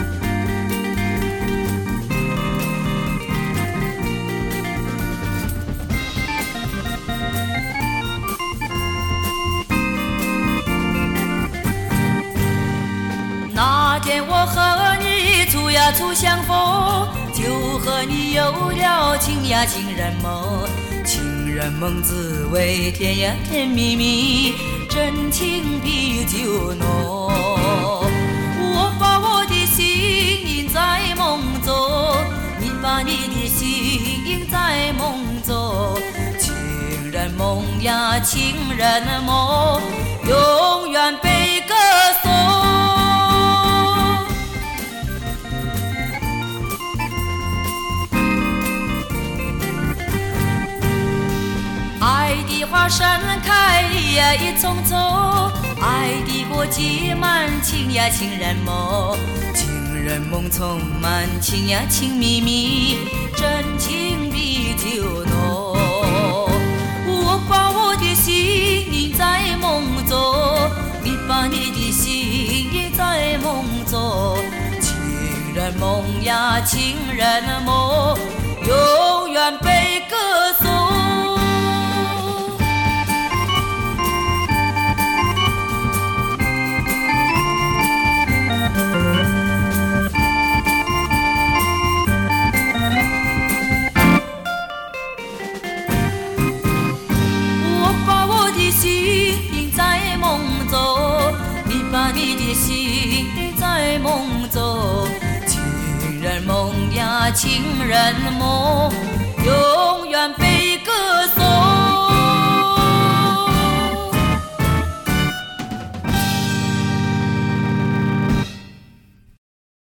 回味从小到大的磁性声音 惟有黑胶原版CD